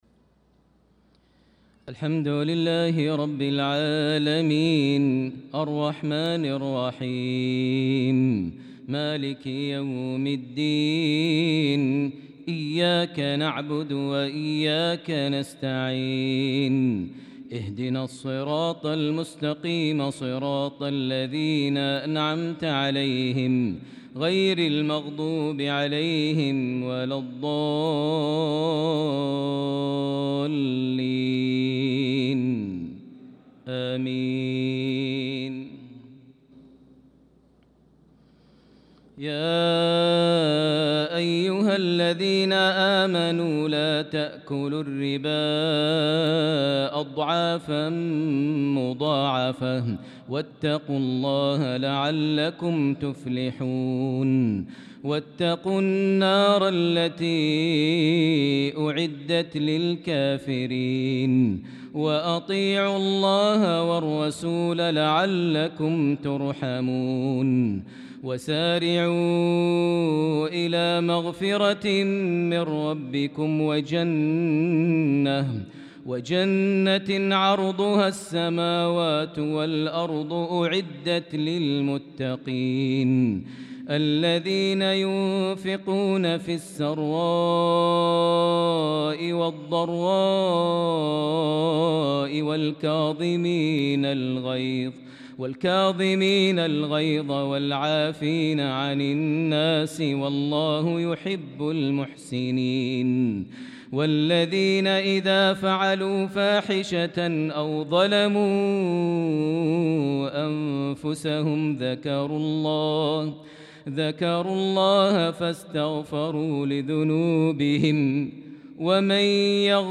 صلاة العشاء للقارئ ماهر المعيقلي 8 ذو القعدة 1445 هـ
تِلَاوَات الْحَرَمَيْن .